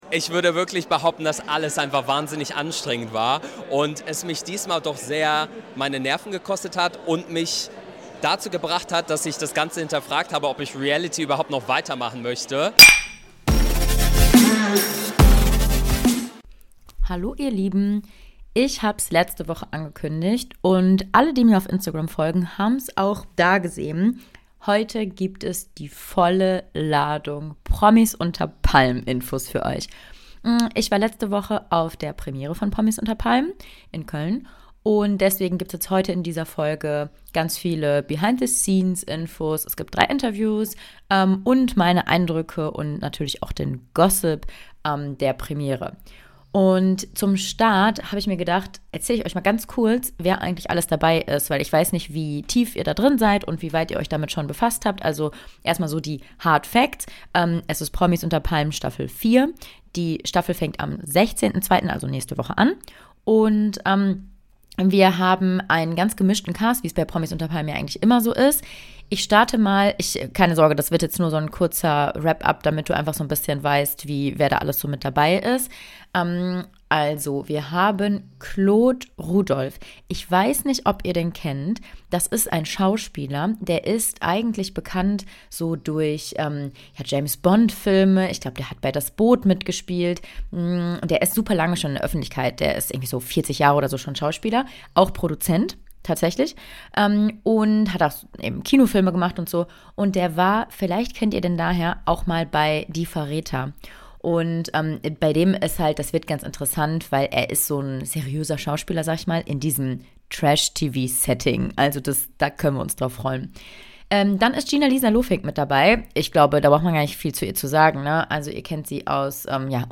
Diese Solo-Folge dreht sich um das Format Promis unter Palmen: Ich gebe euch Behind the scenes Eindrücke der Premiere, Interviews mit Cast-Members und die volle Portion Gossip dazu.